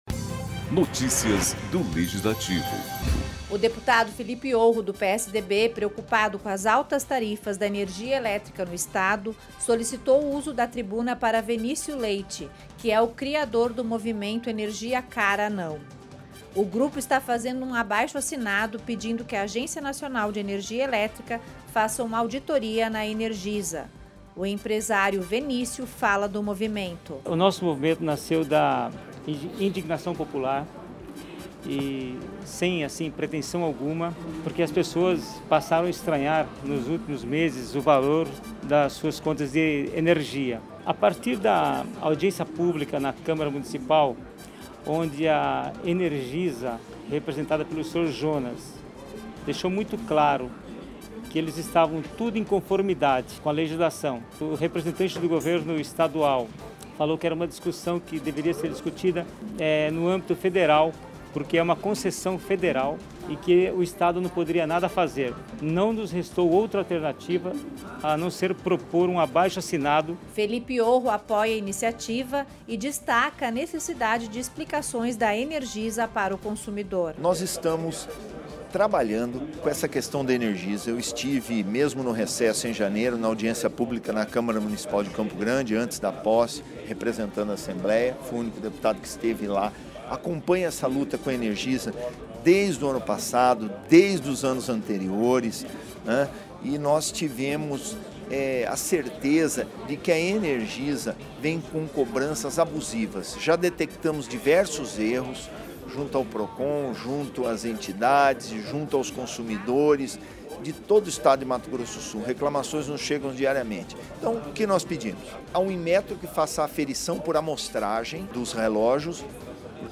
usou a tribuna da Casa de Leis para falar sobre os aumentos abusivos na tariafa elétrica em Mato Grosso do Sul.